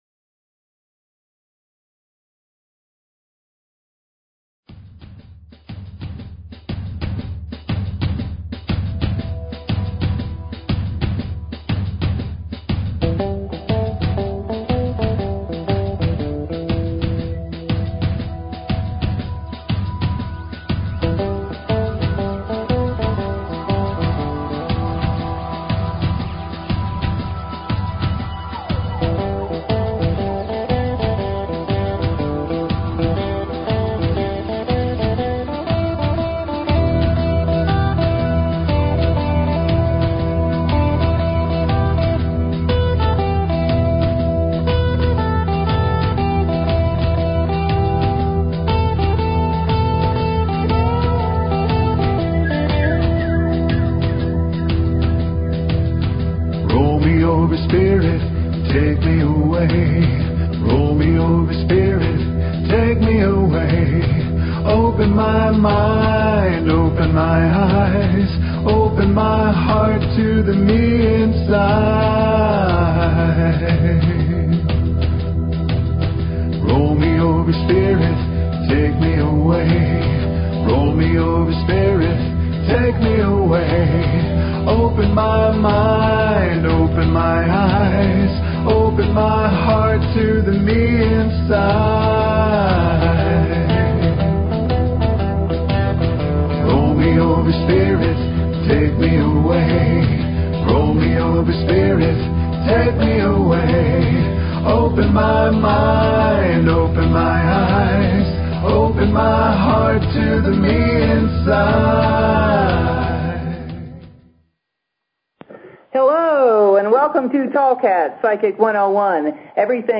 Talk Show Episode, Audio Podcast, Tallkats Psychic 101 and Courtesy of BBS Radio on , show guests , about , categorized as